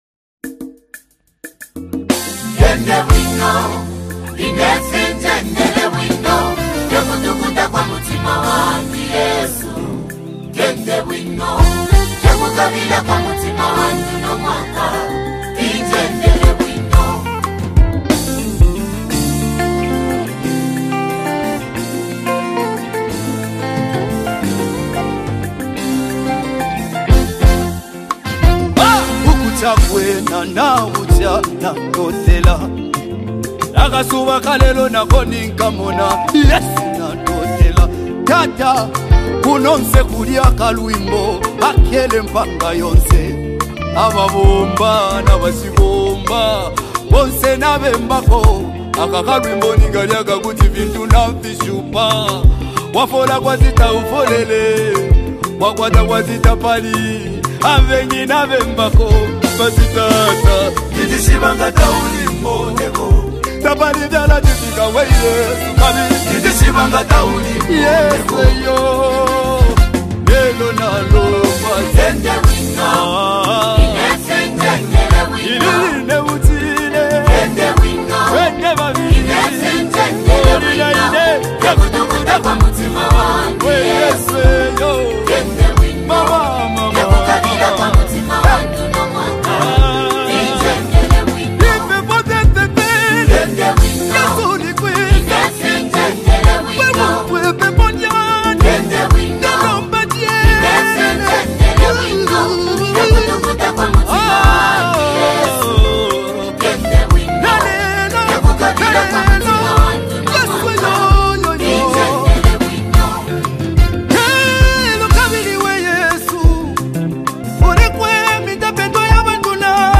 With his distinctive vocal delivery and emotive lyrics